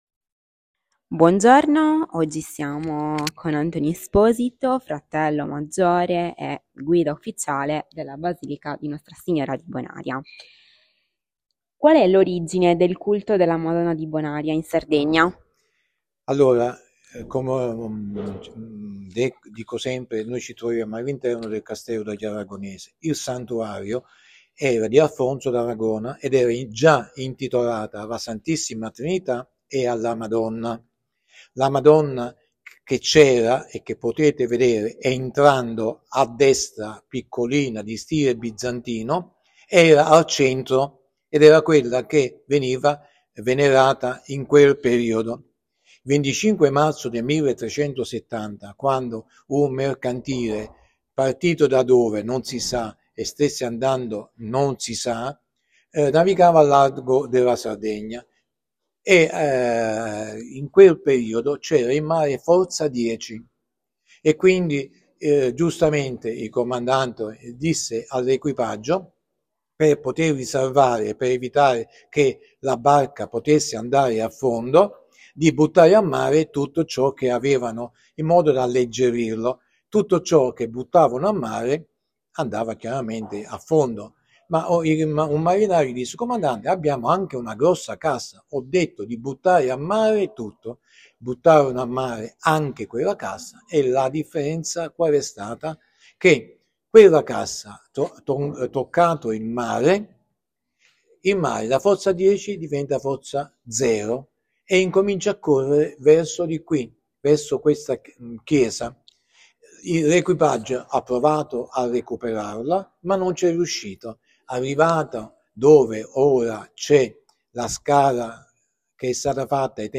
Intervista
Luogo dell'intervista Santuario di Nostra Signora di Bonaria
Apparecchiatura di registrazione Microfono e cellulare